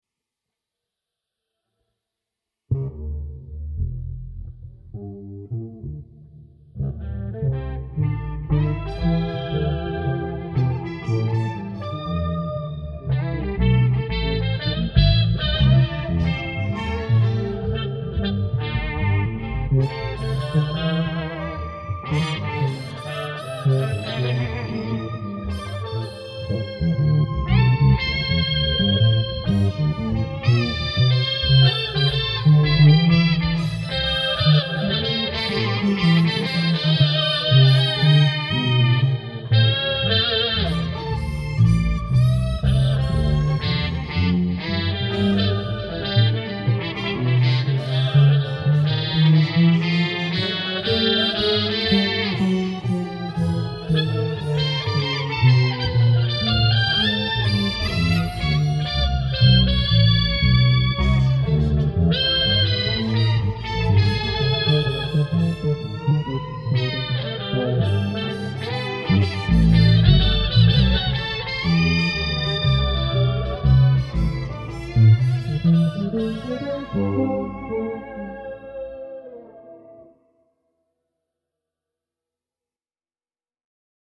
Underneath this somewhere there’s a 12-bar with some extraordinarily pretentious synth-ing.